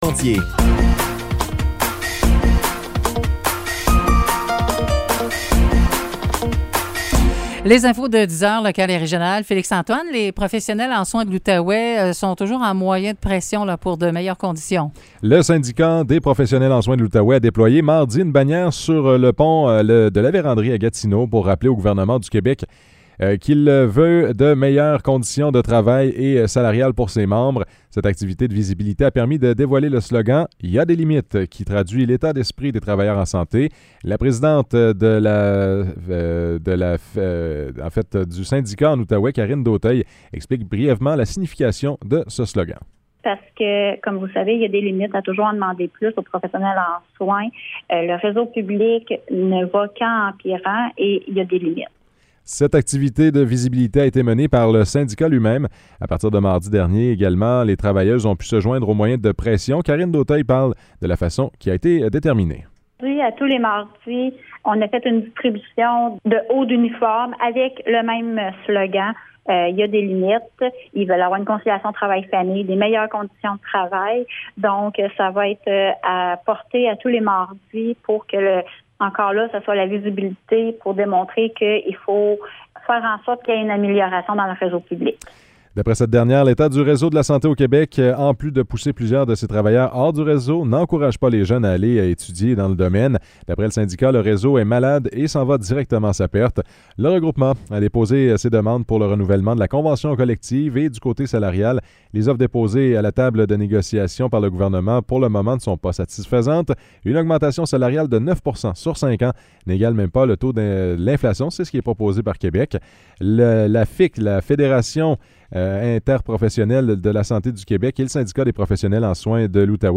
Nouvelles locales - 7 septembre 2023 - 10 h